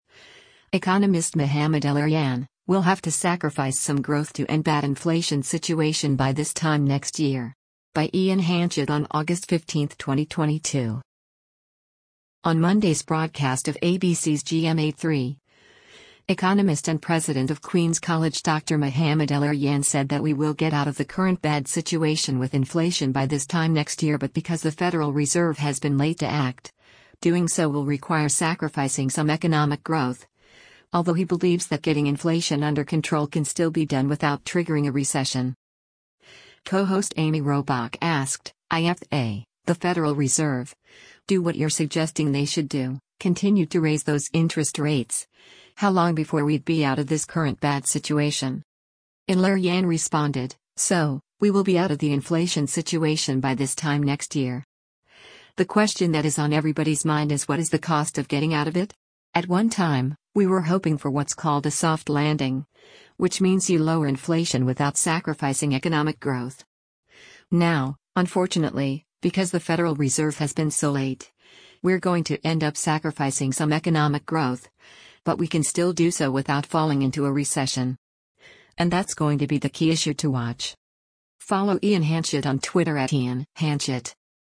On Monday’s broadcast of ABC’s “GMA3,” economist and President of Queens’ College Dr. Mohamed El-Erian said that we will get out of the current bad situation with inflation “by this time next year” but because the Federal Reserve has been late to act, doing so will require “sacrificing some economic growth,” although he believes that getting inflation under control can still be done without triggering a recession.
Co-host Amy Robach asked, “[I]f they [the Federal Reserve] do what you’re suggesting they should do, continue to raise those interest rates, how long before we’d be out of this current bad situation?”